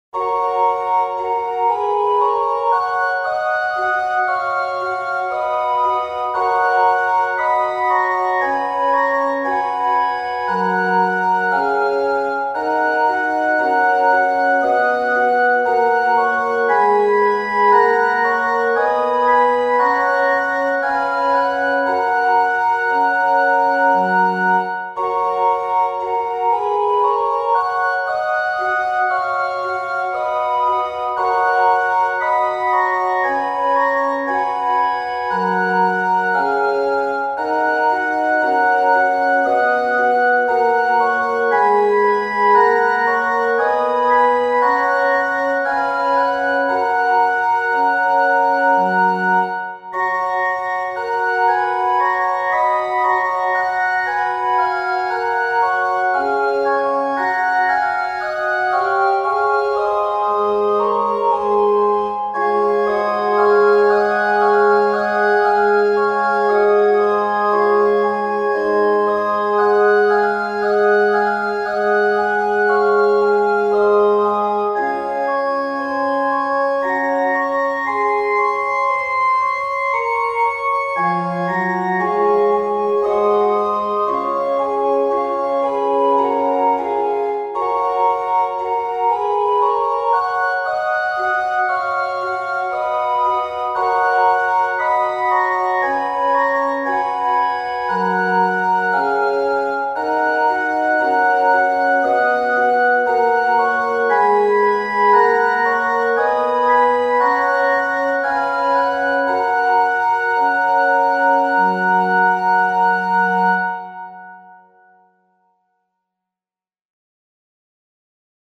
＜オルガン＞
♪オルガンフルートという丸みのある綺麗な音色のパイプオルガンを使用しています。